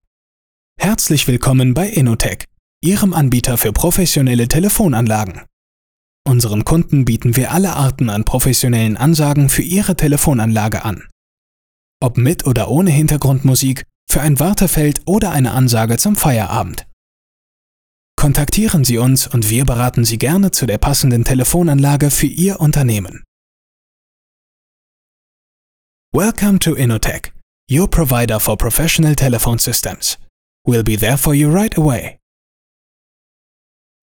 Ansagen
Sprecher männlich 3